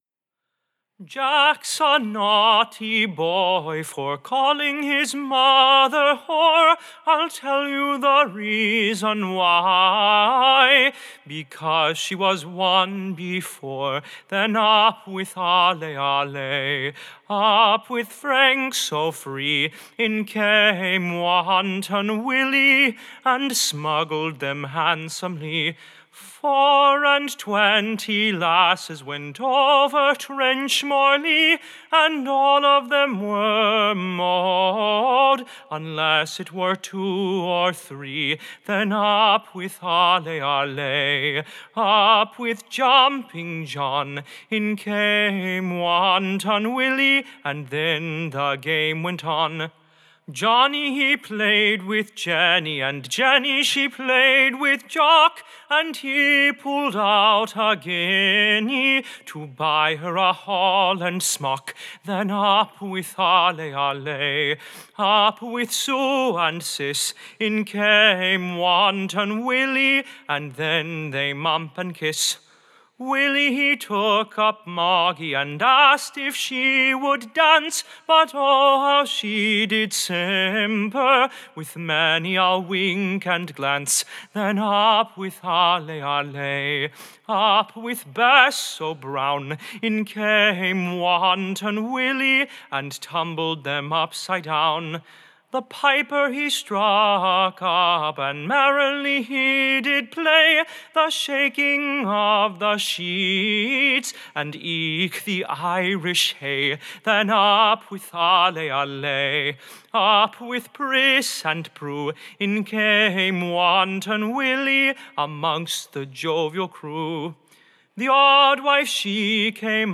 Recording Information Ballad Title The West=Country Jigg: / OR, A Trenchmore Galliard.